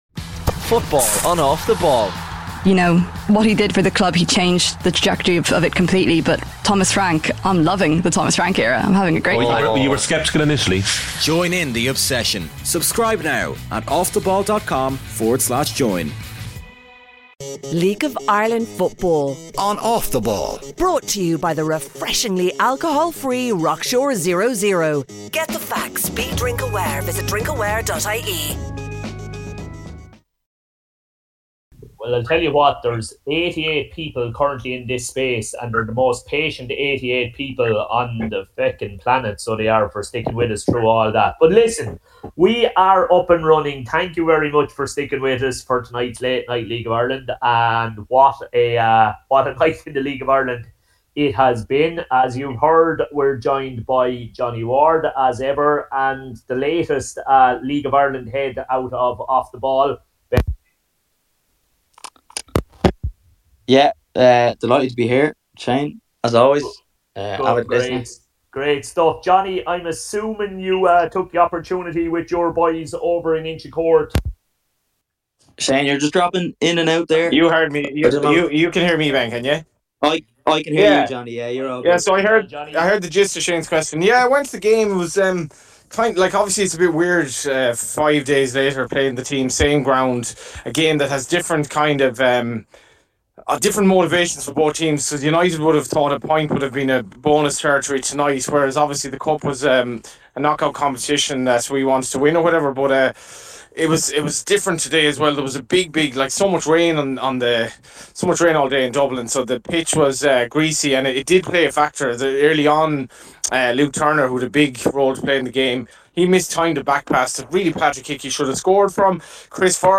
Just the funniest football conversation out there.